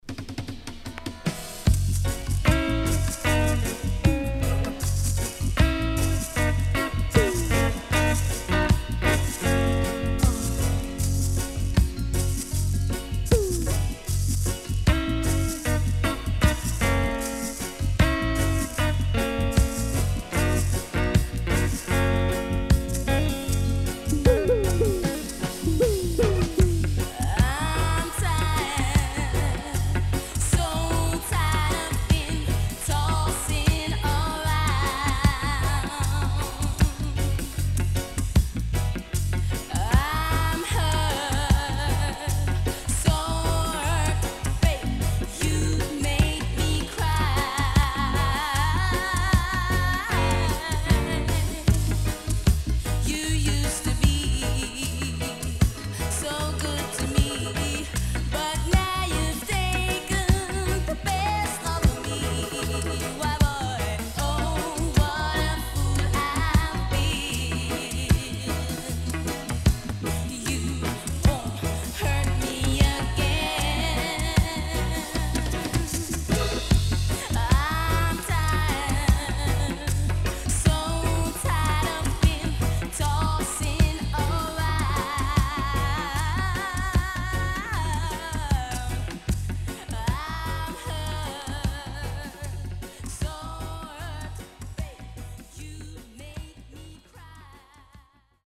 UK Teen Good Lovers Vocal.W-Side Great!!
SIDE A:少しチリノイズ、プチノイズ入ります。